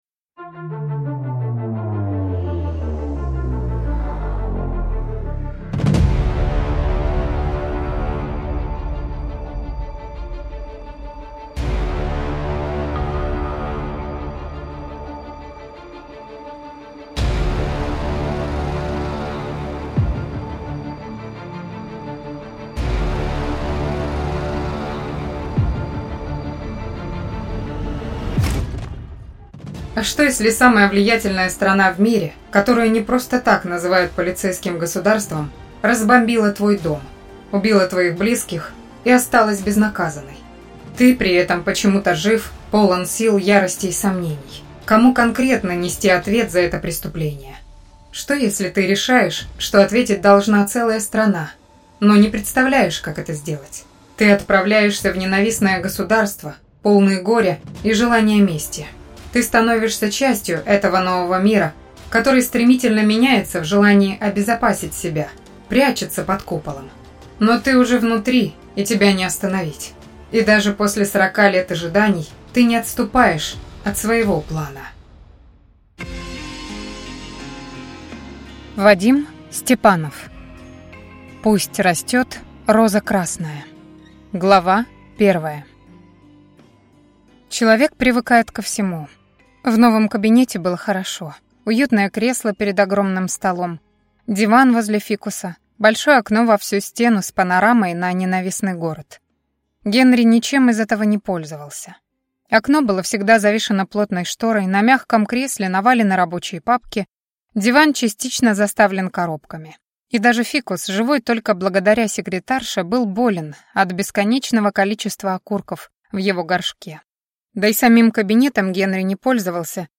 Аудиокнига Пусть растет роза красная | Библиотека аудиокниг